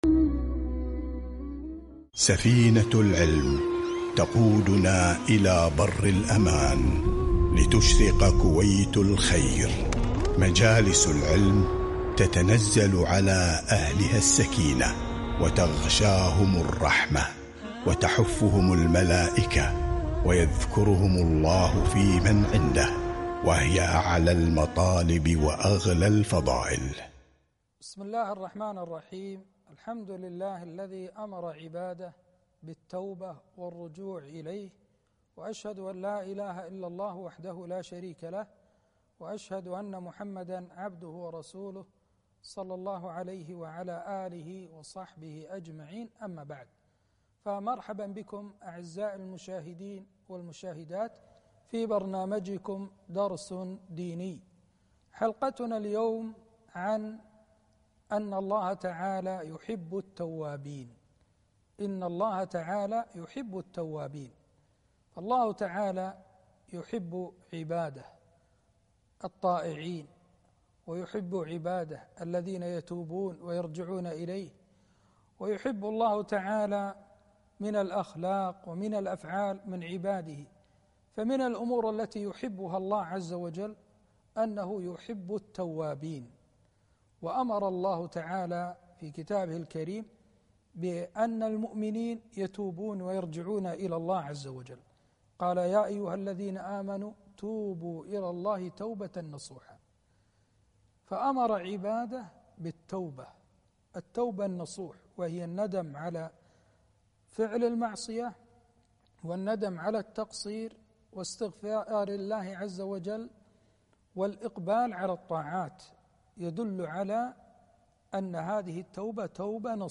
لقاء إذاعي- إن الله يحب التوابين